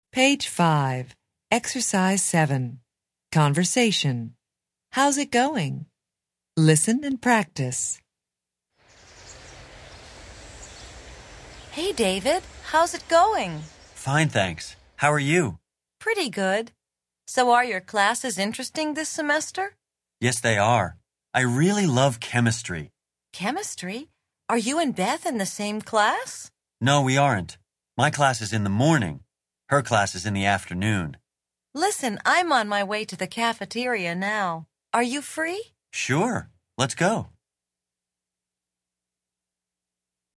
Interchange Third Edition Level 1 Unit 1 Ex 7 Conversation Track 3 Students Book Student Arcade Self Study Audio
interchange3-level1-unit1-ex7-conversation-track3-students-book-student-arcade-self-study-audio.mp3